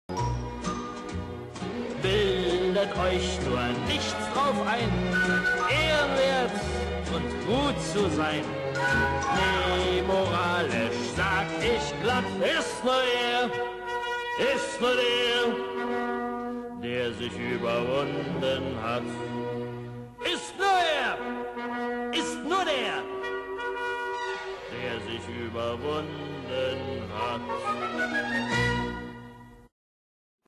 Musikalisches Lustspiel in sechs Bildern